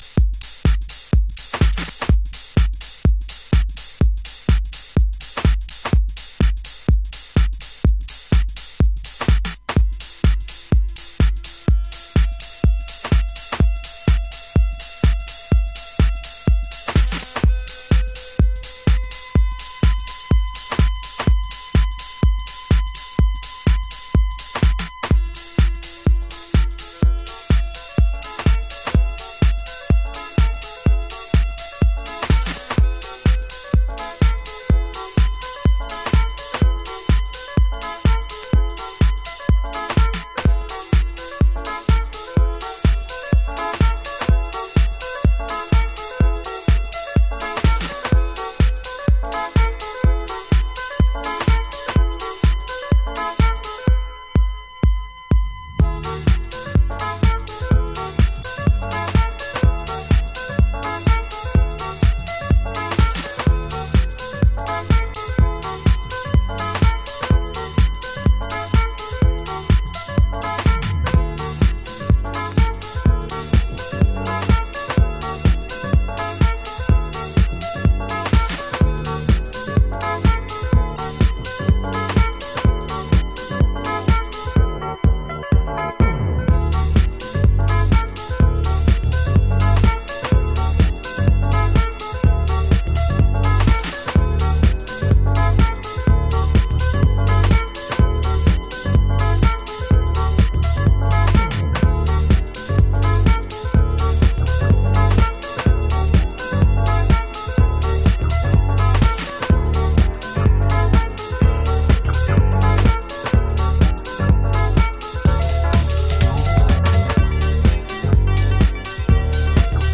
FORMAT - 12inch
スクラッチを交えたビートとちょっと切ない手引きの鍵盤、グイグイ上昇していくベースラインが絶妙なグレイトハウストラック